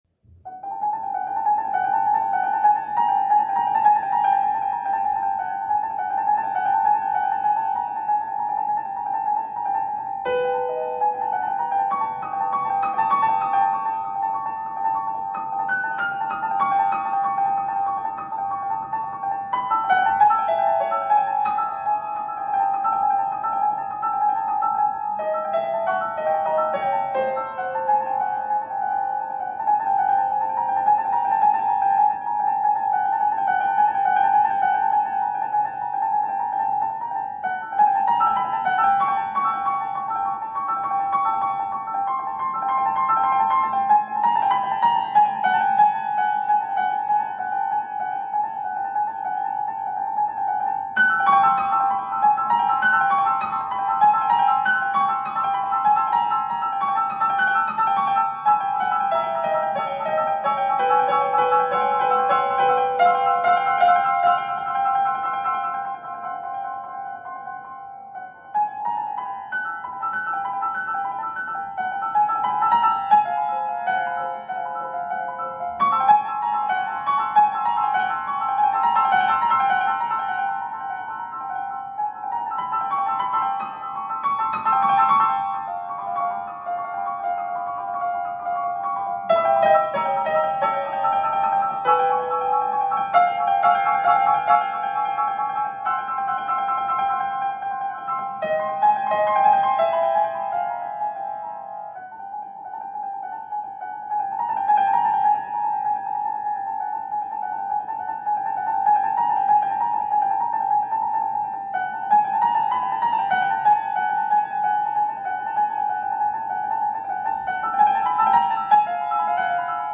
Instrumentación: piano solo